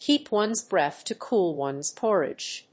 アクセントkéep [sáve] one's bréath to cóol one's pórridge